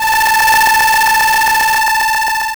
AlienRingtone.wav